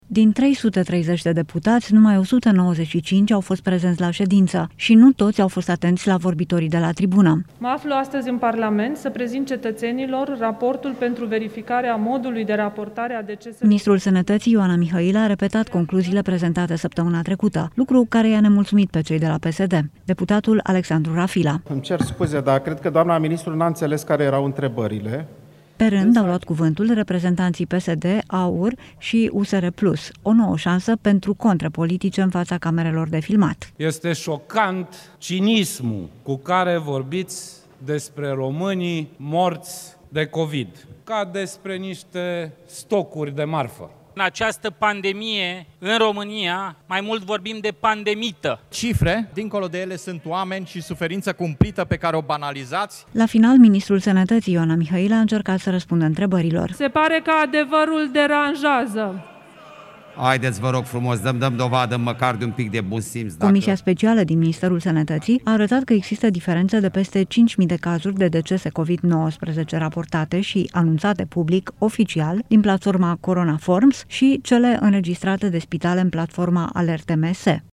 Din 330 de deputați, numai 195 au fost prezenți la ședință și nu toți au fost atenți la vorbitorii de la tribună.
Pe rând, au luat cuvântul reprezentanții PSD, AUR șu USR PLUS – o nouă șansă pentru contre politice în fața camerelor de filmat:
La final, Ministrul Sănății, Ioana Mihăila a încercat să răspundă întrebărilor: